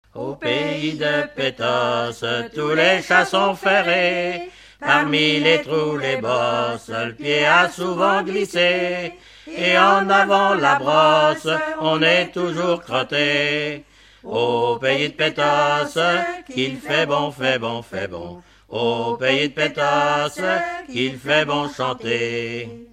Enquête Arexcpo en Vendée-Pays Sud-Vendée
Pièce musicale inédite